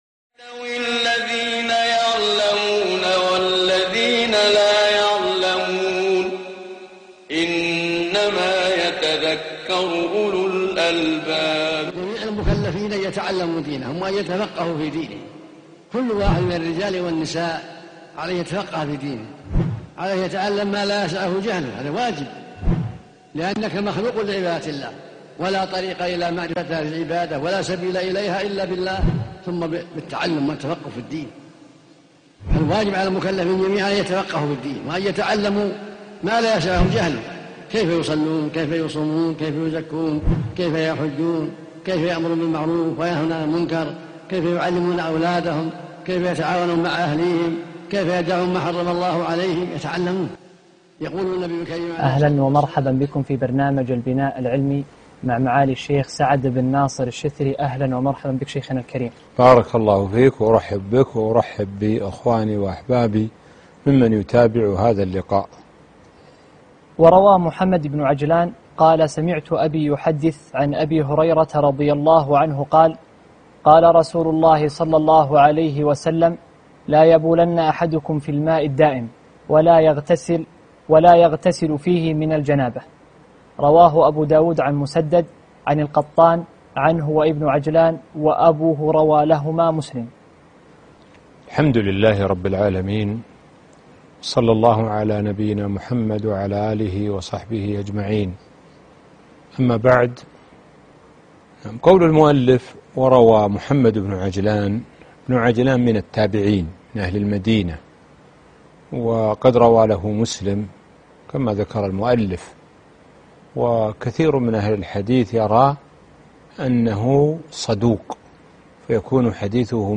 الدرس 2 (المحرر في الحديث- البناء العلمي